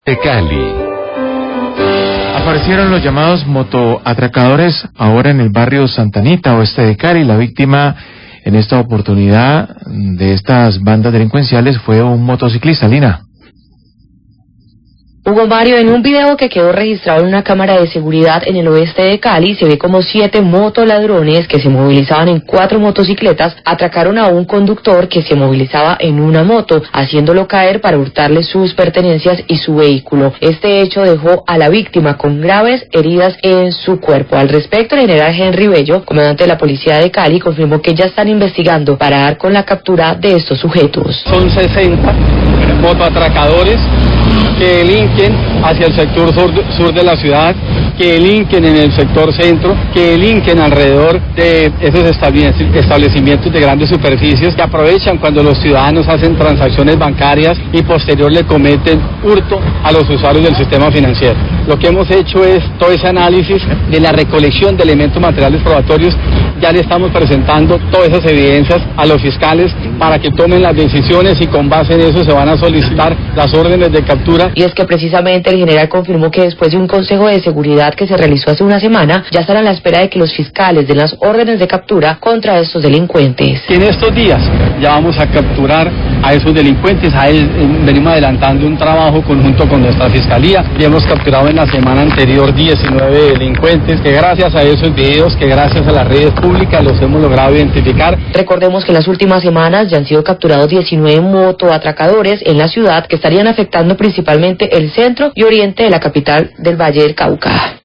Radio
Aparecieron de nuevo los motoladrones atracando a un motociclista en el barrio Santa Anita. Siete motoladrones en 4 motos abordaron y tumbaron a la víctima para robarle su vehículo y objetos personales. Habla Henry Bello, comandante de Policía Cali, y dice que ya están investigando para dar con la captura de estos sujetos. están a la espera de que la Fiscalía de orden de captura contra ellos.